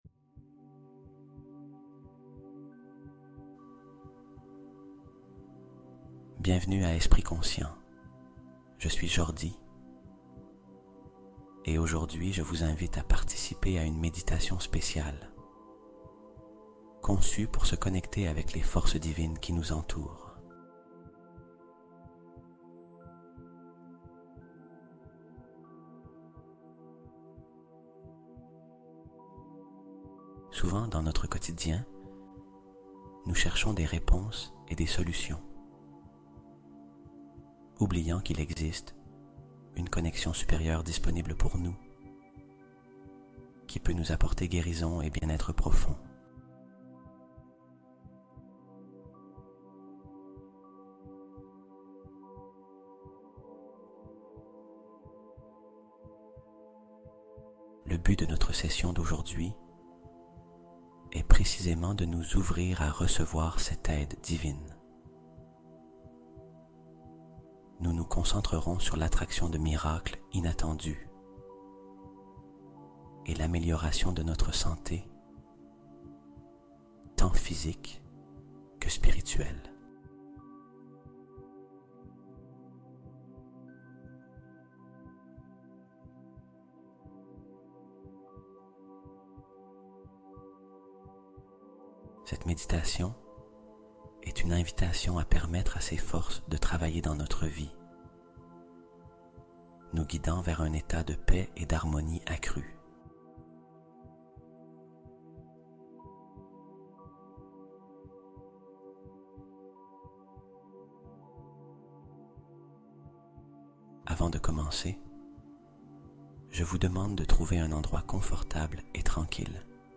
Fréquence 999 Hz : Apaiser le système nerveux et retrouver un calme profond